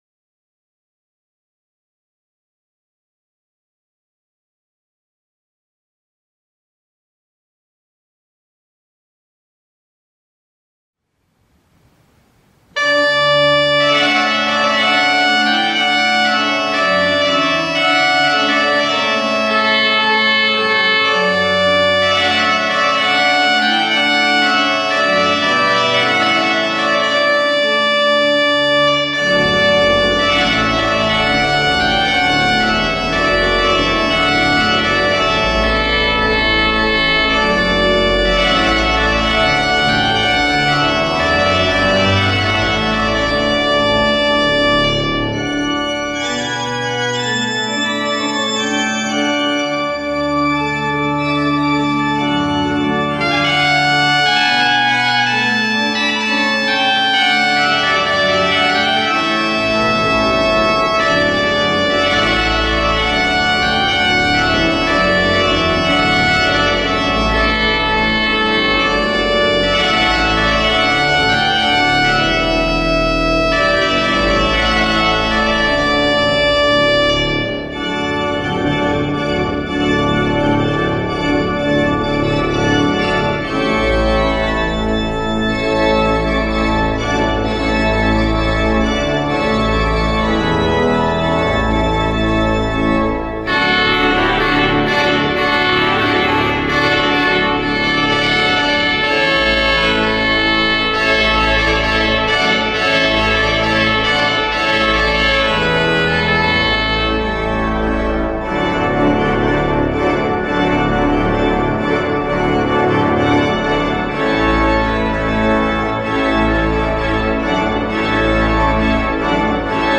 We’ve prepared this page to help you choose organ music for your wedding ceremony.
A. Grand & Glorious